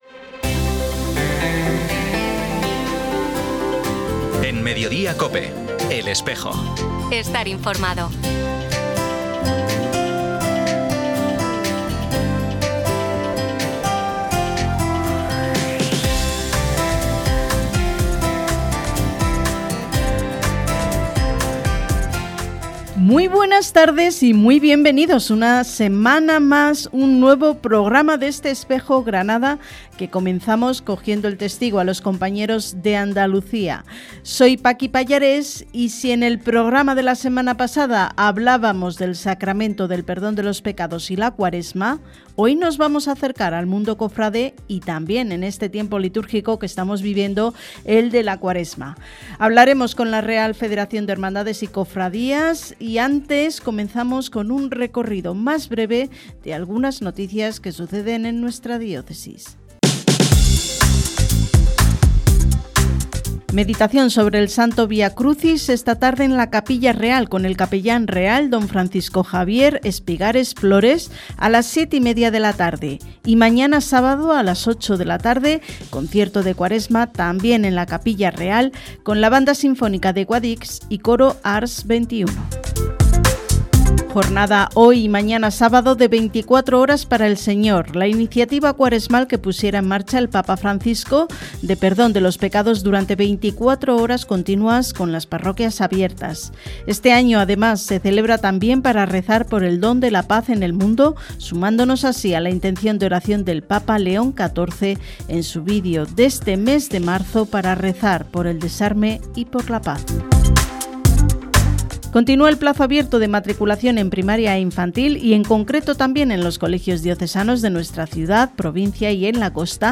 Entrevista con la Real Federación, en “El Espejo” - Archidiócesis de Granada
En el programa emitido hoy 13 de marzo, en COPE Granada.